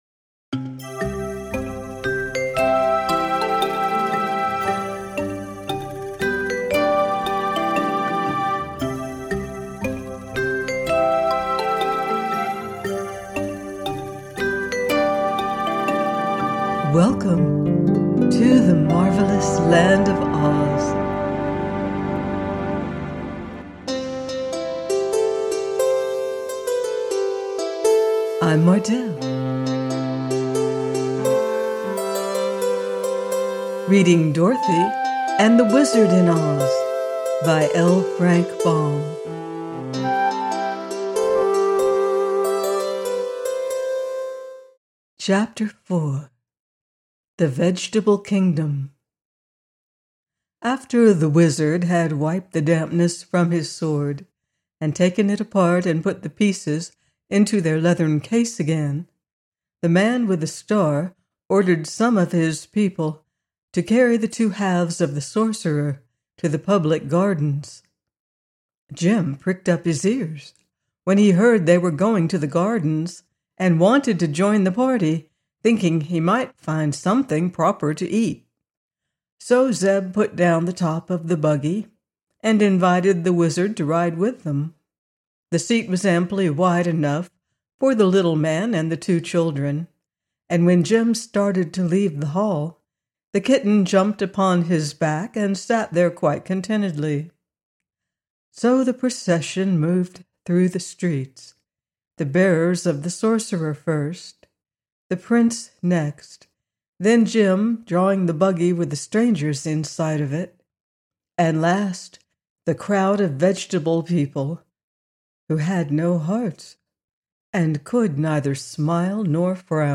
DOROTHY AND THE WIZARD IN OZ: by L. Frank Baum - audiobook